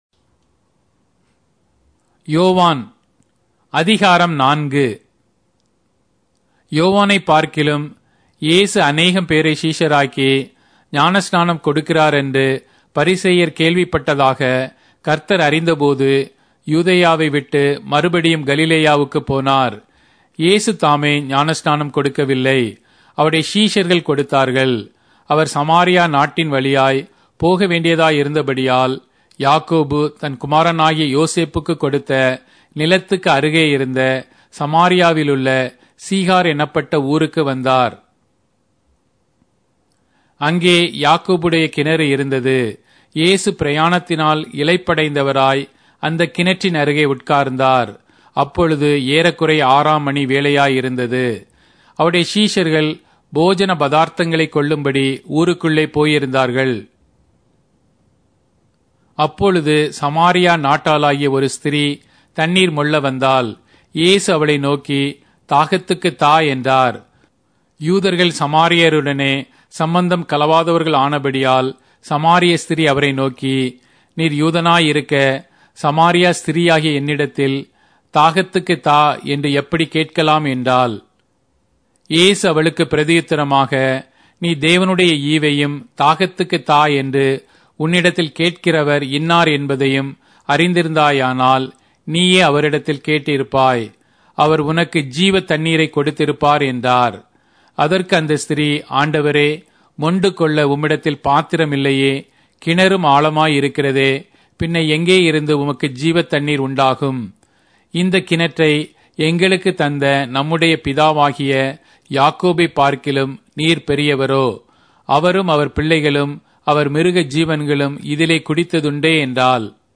Tamil Audio Bible - John 15 in Ocvkn bible version